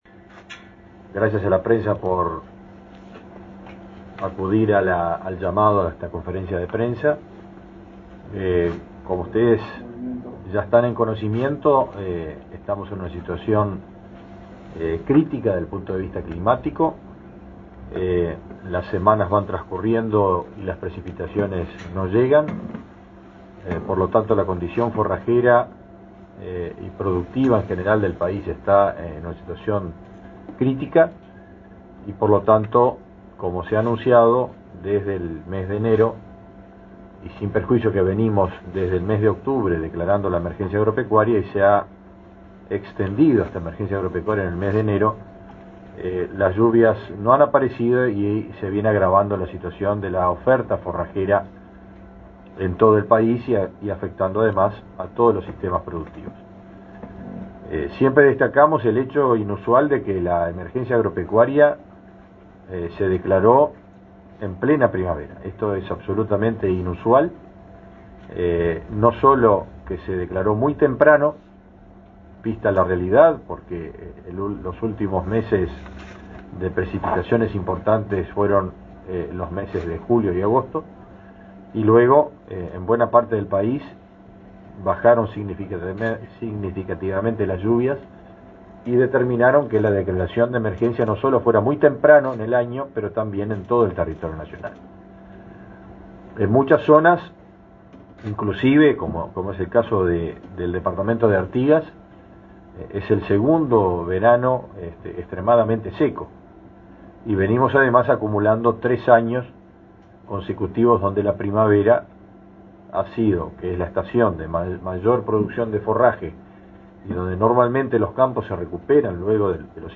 Conferencia del ministro de Ganadería, Fernando Mattos
Conferencia del ministro de Ganadería, Fernando Mattos 10/03/2023 Compartir Facebook X Copiar enlace WhatsApp LinkedIn El titular del Ministerio de Ganadería, Agricultura y Pesca, Fernando Mattos, brindó una conferencia de prensa para informar acerca de las medidas adoptadas por esa cartera para pequeños productores debido a la sequía.